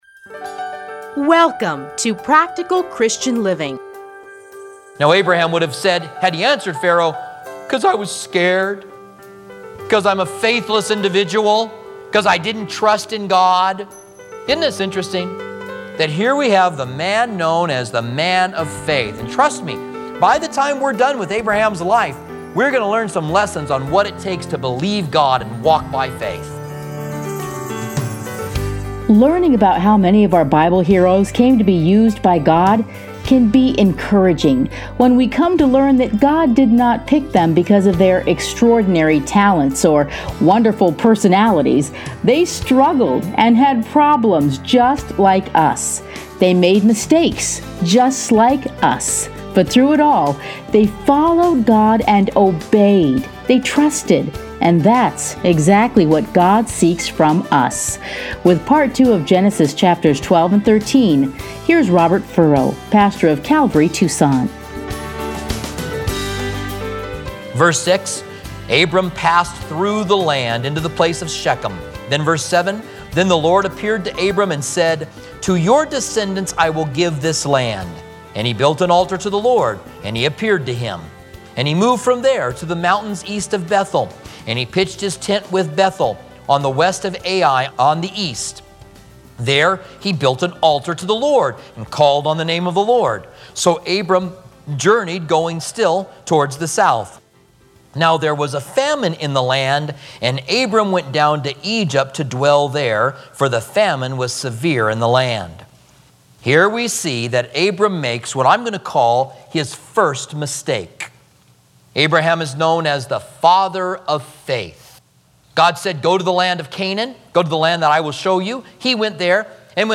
Listen here to a teaching from Genesis.